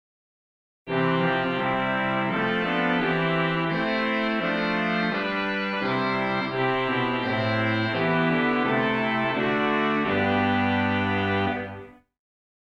A generic term for a fairly wide variety of reed stops.
Comes in a wide variety of dynamics and brightness.
Trompete 8', Swell First Baptist Church, Riverside, California, USA Schantz, 1966 arpeggio
Sw_Trompete8_stan.mp3